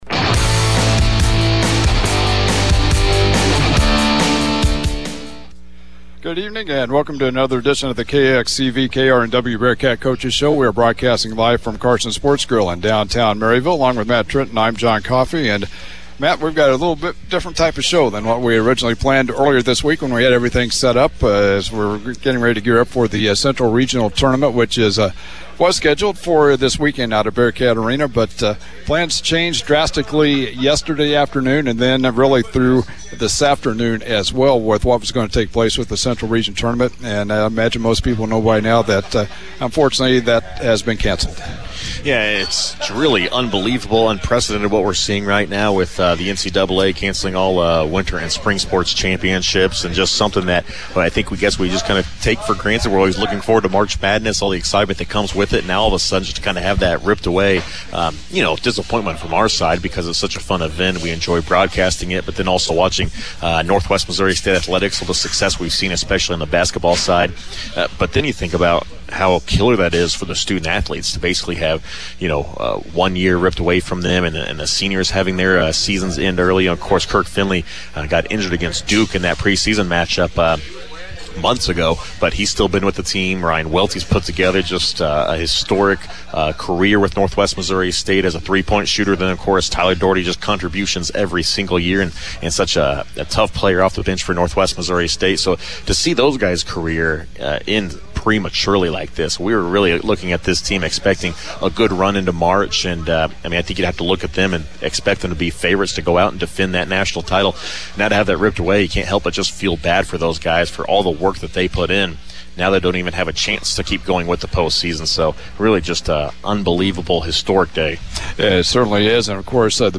Download .mp3 Northwest coaches and administrators joined the Bearcat Coaches Show Thursday evening to discuss the NCAA's decision to cancel the winter and spring championships due to the spread of the coronavirus.